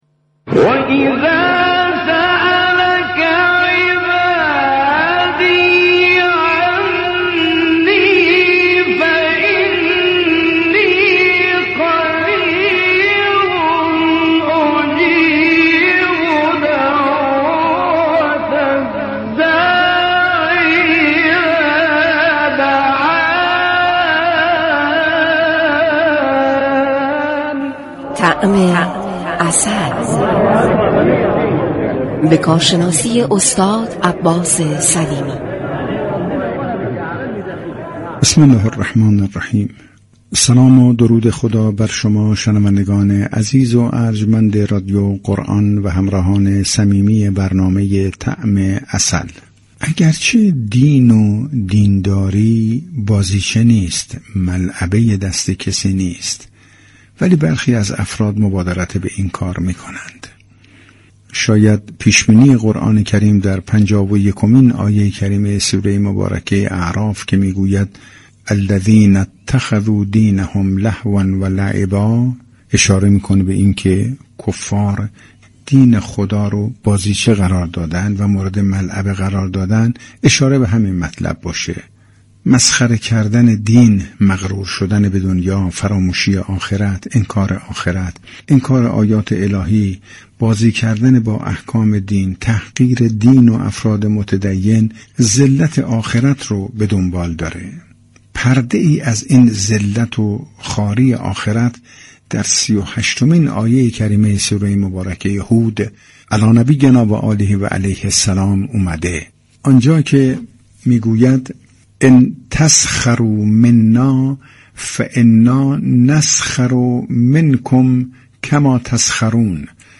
مجله صبحگاهی "تسنیم" با رویكرد اطلاع رسانی همراه با بخش هایی متنوع، شنبه تا پنجشنبه از شبكه ی رادیویی قرآن به صورت زنده تقدیم شنوندگان می شود.